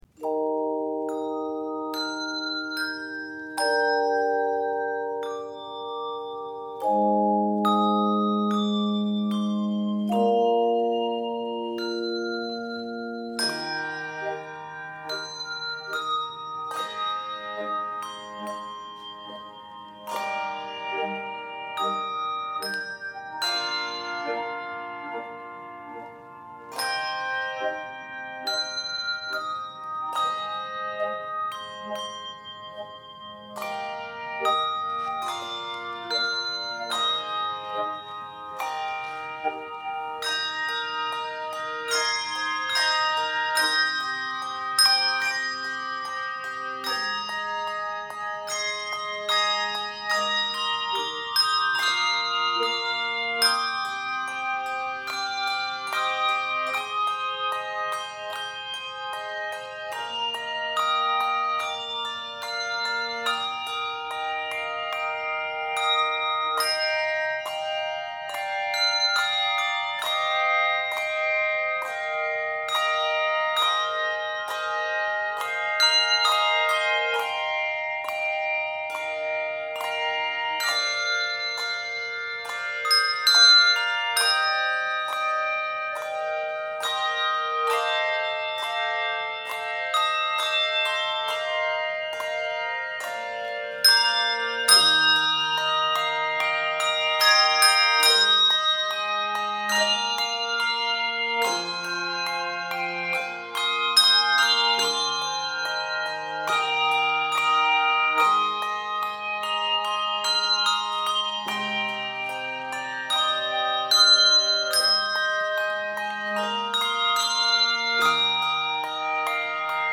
Key of Eb Major.